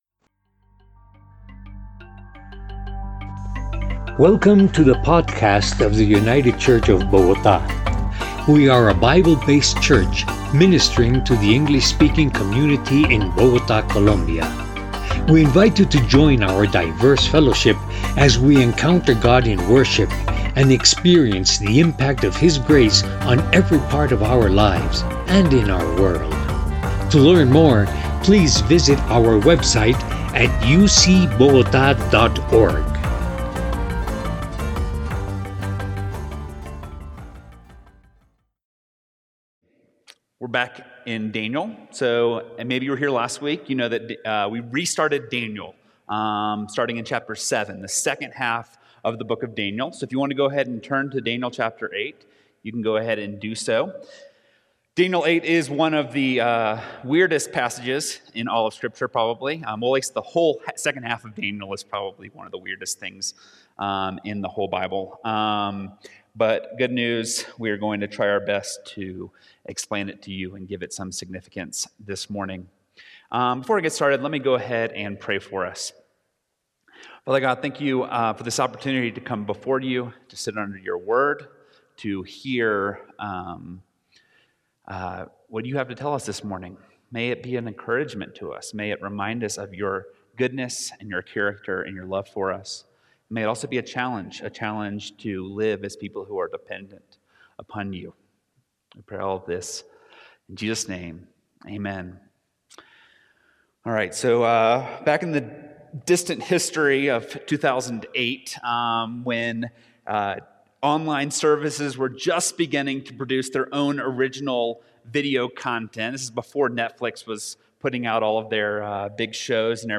Ctrl Alt Delete – United Church of Bogotá